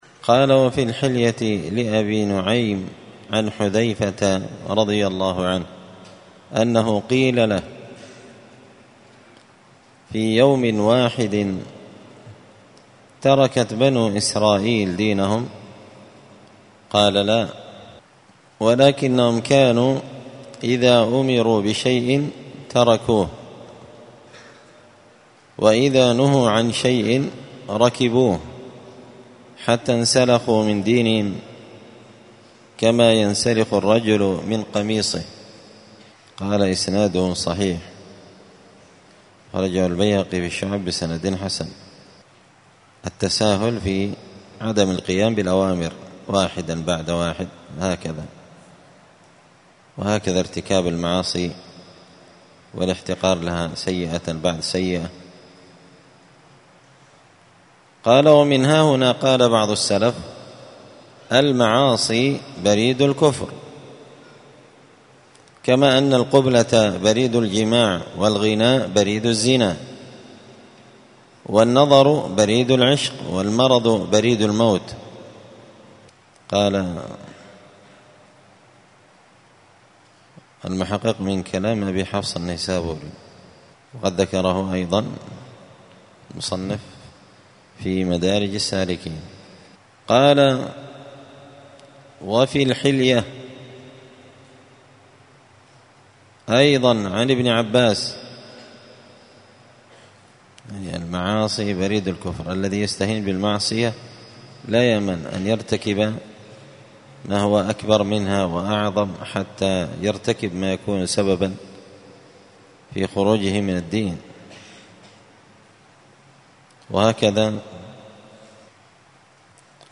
الجمعة 10 جمادى الأولى 1445 هــــ | الداء والدواء للإمام ابن القيم رحمه الله، الدروس، دروس الآداب | شارك بتعليقك | 66 المشاهدات
مسجد الفرقان قشن_المهرة_اليمن